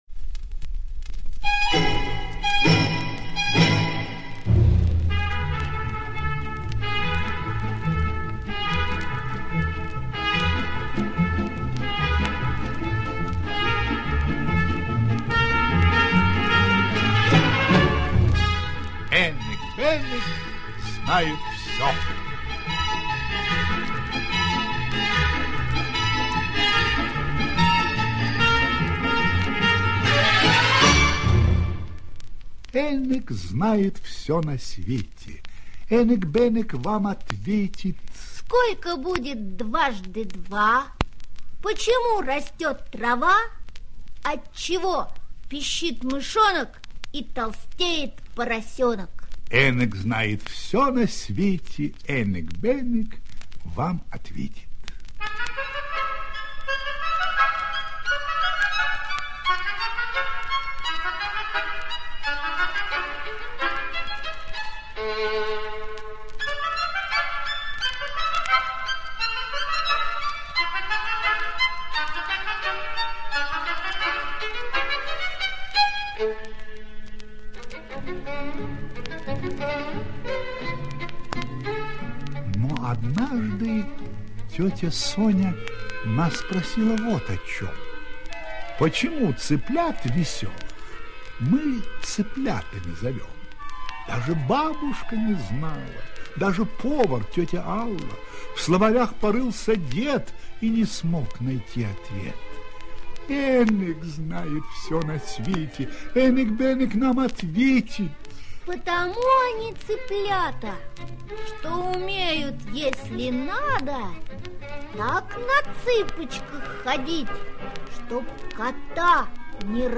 Энык-Бенык знает все - аудиосказка Дриза - слушать онлайн